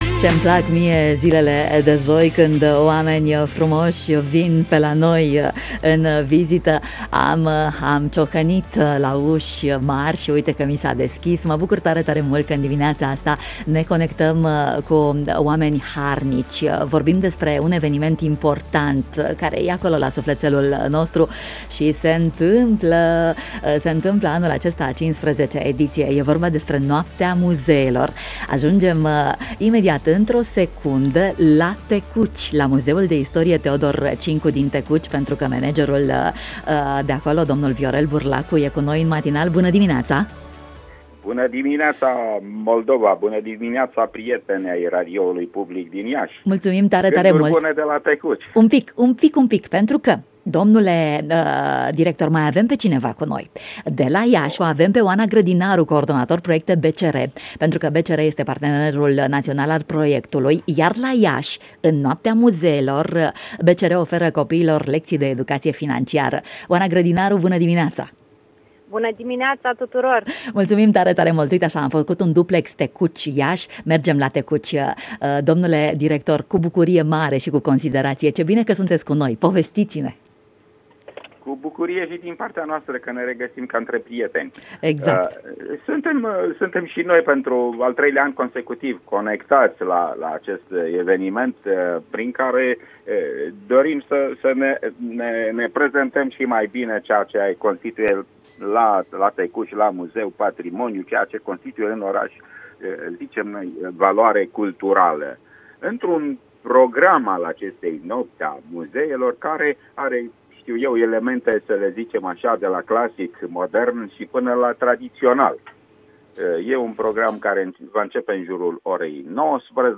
În matinalul Radio România Iași, joi, 16 mai 2019, la 7.50 ⏰, ne conectam cu Muzeul de Istorie “Teodor Cincu”, Tecuci.